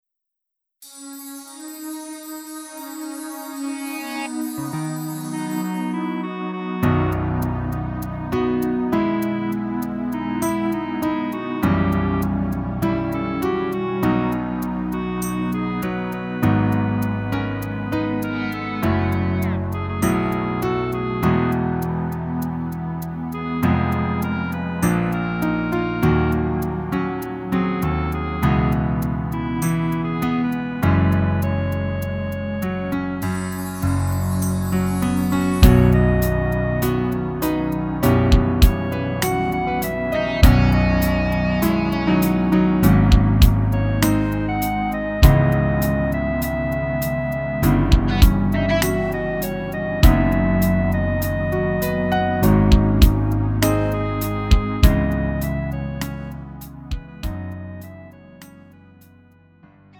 음정 -1키 3:37
장르 가요 구분 Lite MR
Lite MR은 저렴한 가격에 간단한 연습이나 취미용으로 활용할 수 있는 가벼운 반주입니다.